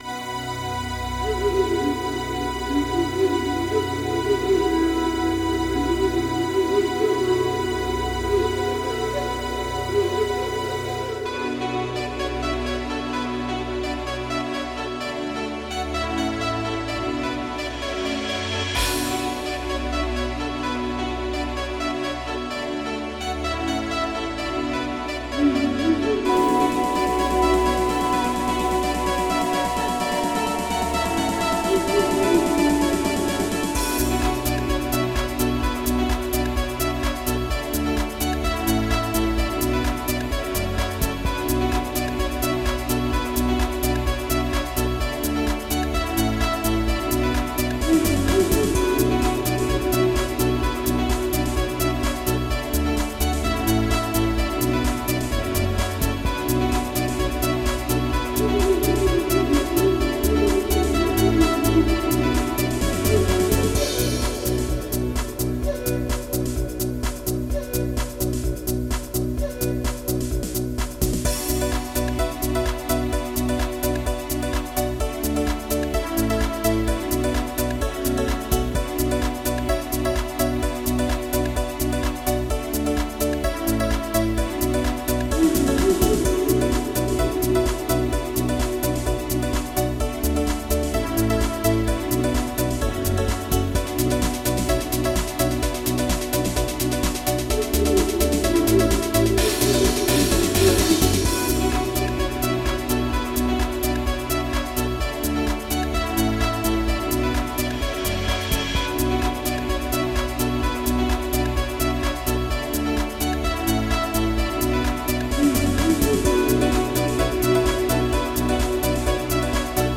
MIDI-fied version